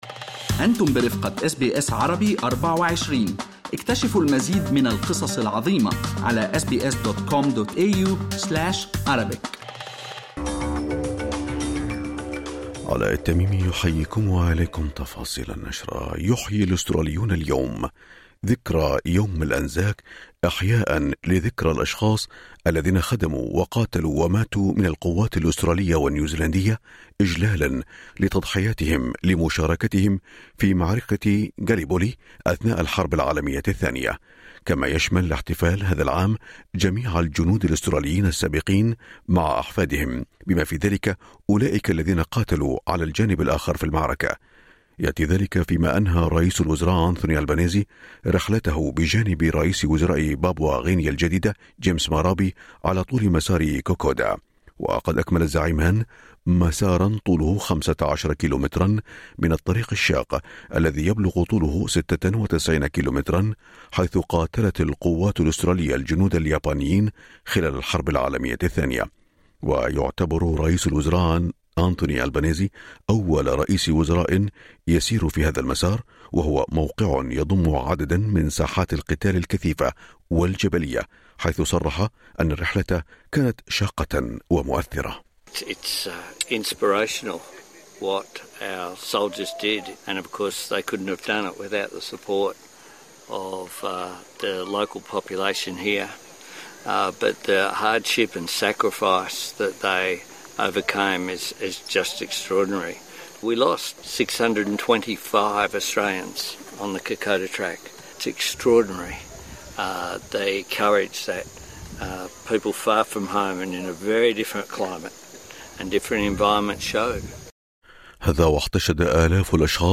نشرة أخبار الصباح 25/4/2024